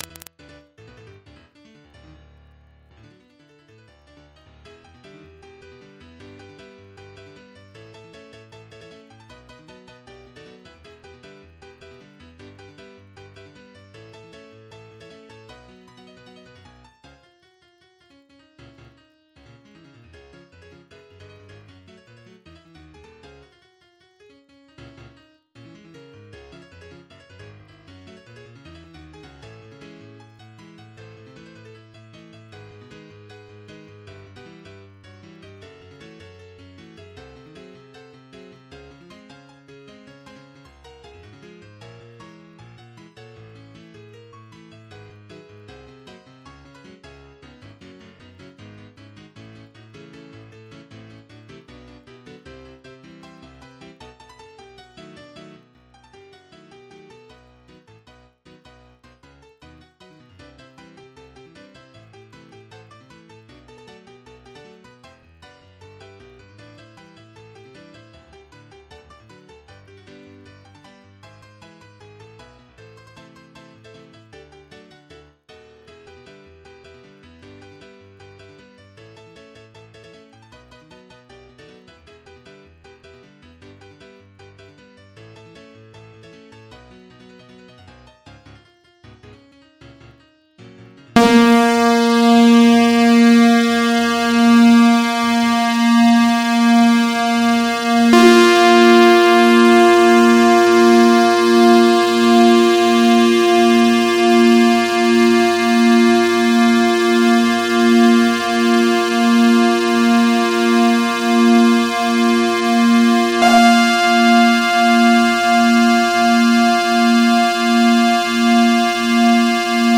MIDI 73.82 KB MP3 (Converted) 3.69 MB MIDI-XML Sheet Music